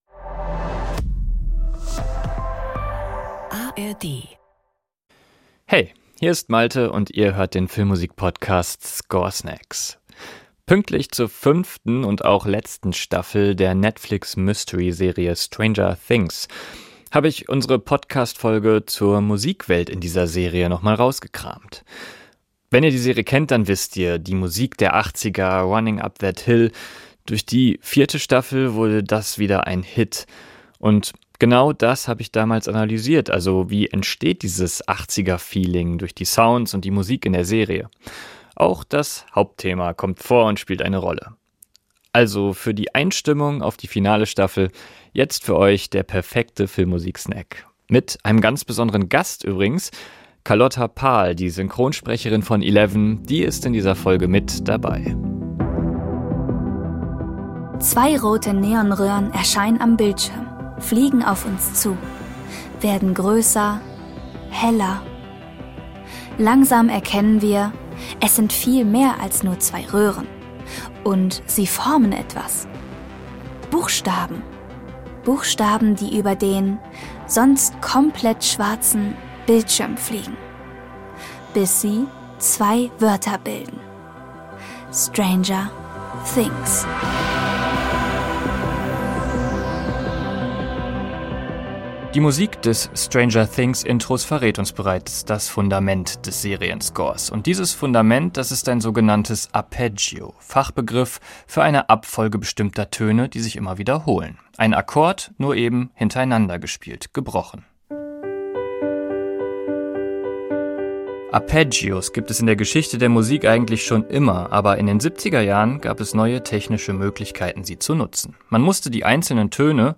Synthesizer, die diese perfekte 80er Klangwelt erzeugen.